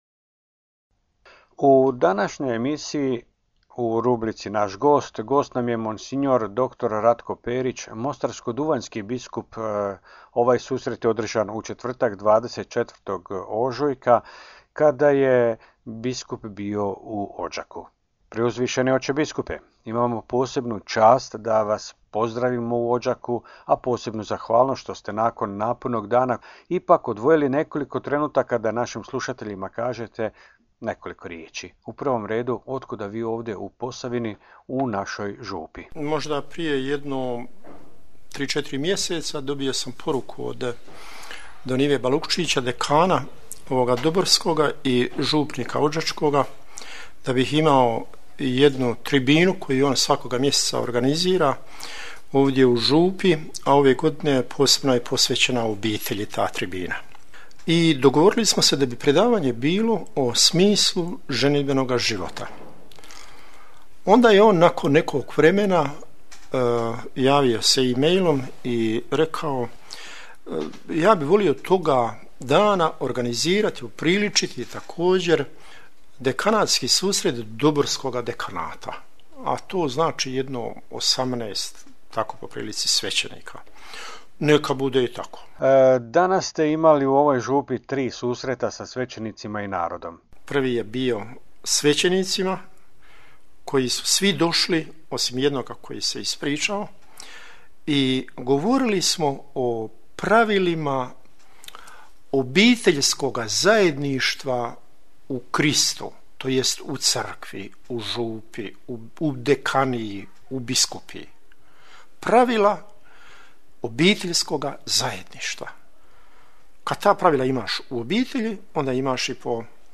Audio: Razgovor s biskupom Perićem
Biskup mostarsko-duvanjski i apostolski upravitelj trebinjsko-mrkanski mons. dr. Ratko Perić gostovao je u emisiji "Glas vjere" Radio postaje Odžak: